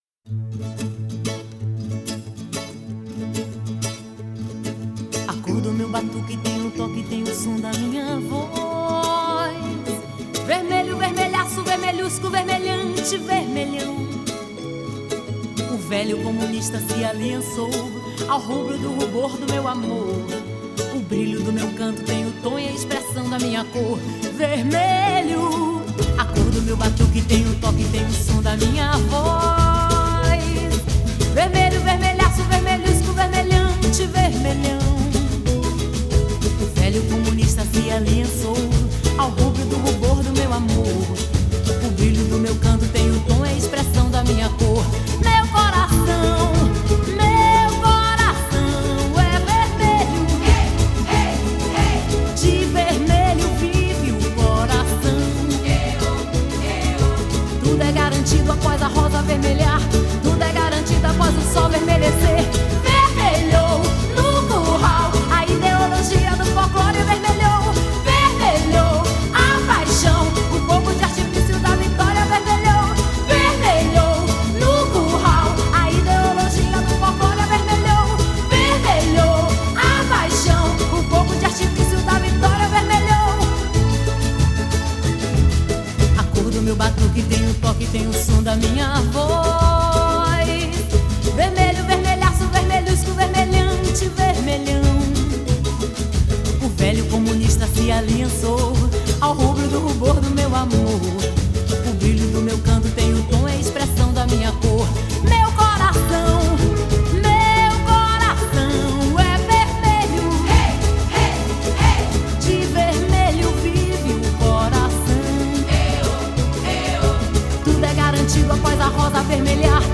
2025-01-06 00:15:20 Gênero: Axé Views